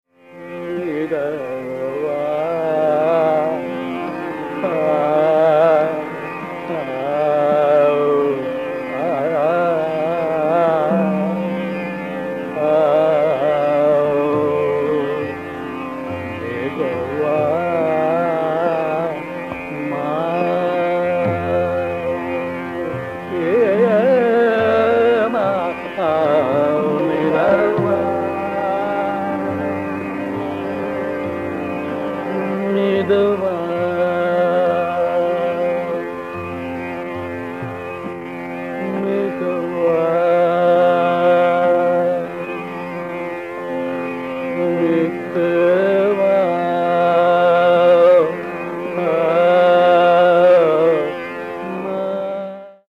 Neelambari | SRgGmPDnS | Hindustani Raga Index | 365+ Ragas
S-R-gG-m-P-D-n-S
—Omkarnath Thakur (~1960s)—
[refrain, e.g. 0:51] S(GRmGPm)Gm RS, n(DPD) ngR, Rg(R)S, RS (SN)S, (N)SRgRS, (SN)S n(SD), R, S…
• Tanpura: Sa–Pa
AUD2-Neelambari-Omkarnath-Thakur.mp3